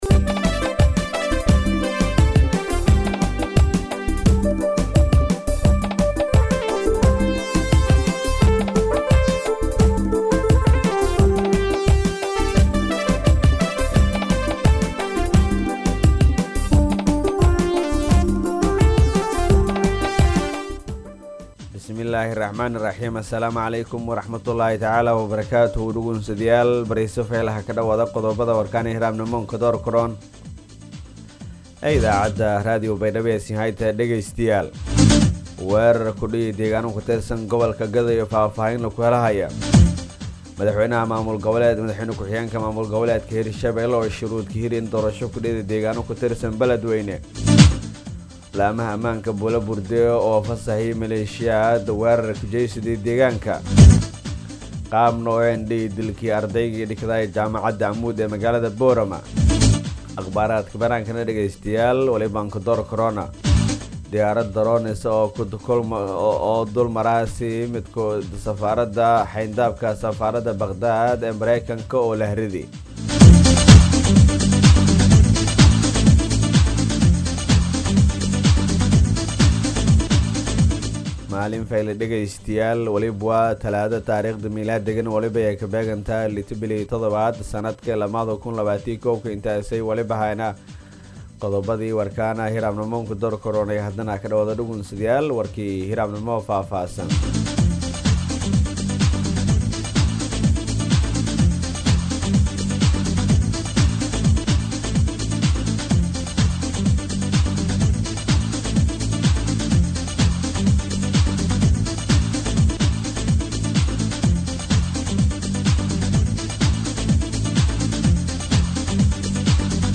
DHAGEYSO:- Warka Subaxnimo Radio Baidoa 6-7-2021